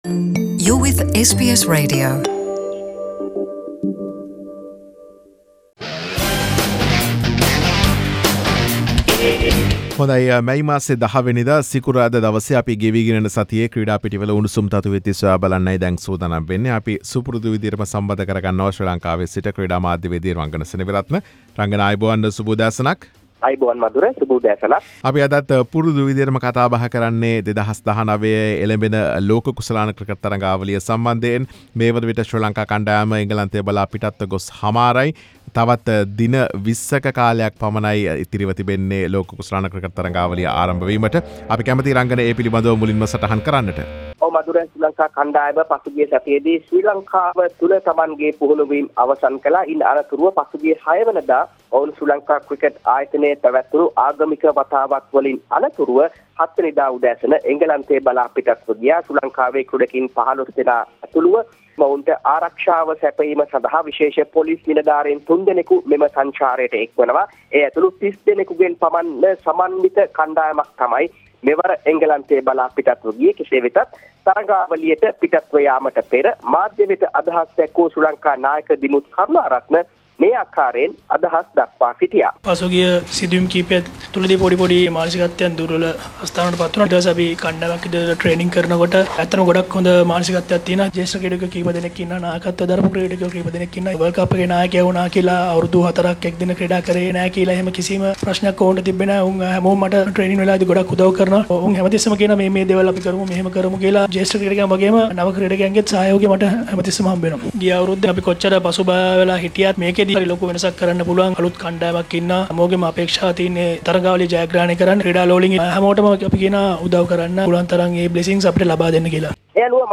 SBS Sinhalese Weekly Sports Round Up.